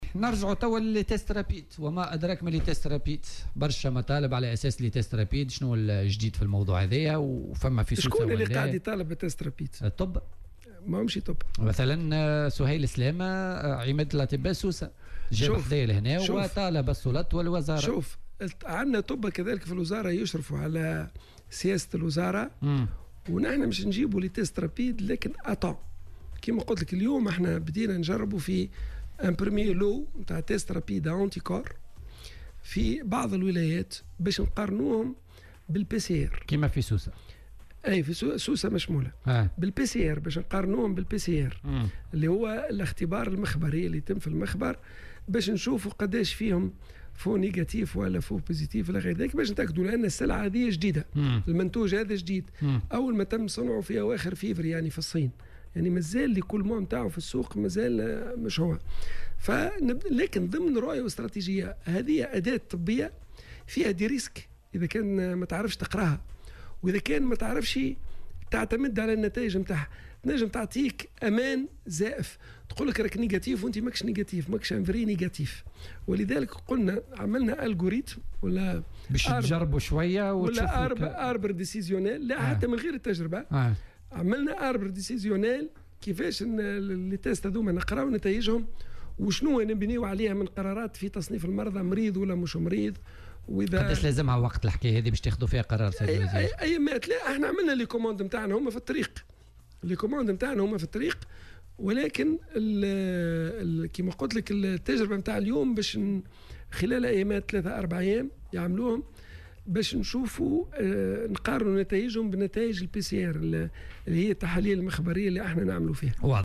وأوضح في مداخلة له اليوم في برنامج "بوليتيكا" أنه تم الانطلاق في بعض الولايات فقط باستعمال شرائح الفحص السريعة وذلك بهدف مقارنة نتائجها بنتائج التحاليل المخبرية.